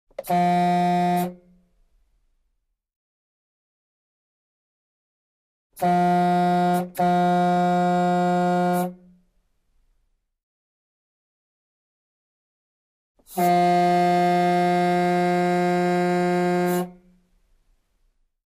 Звуки тягача